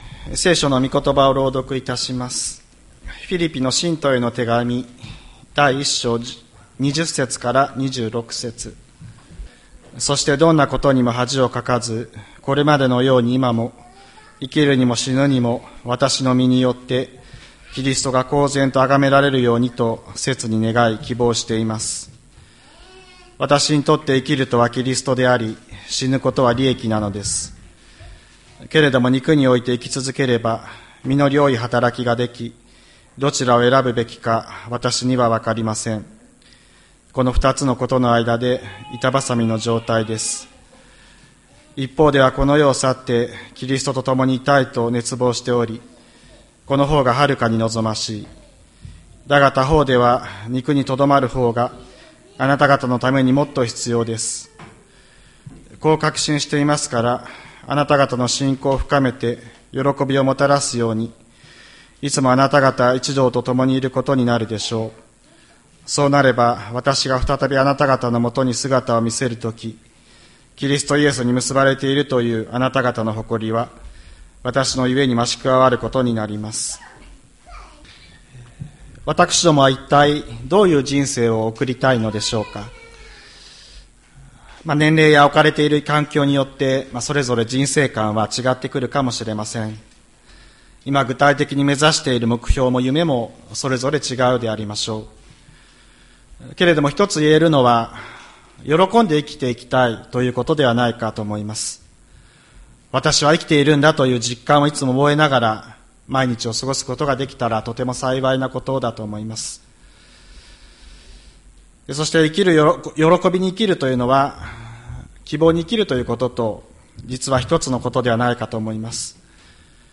2024年09月08日朝の礼拝「生きるとはキリスト」吹田市千里山のキリスト教会
千里山教会 2024年09月08日の礼拝メッセージ。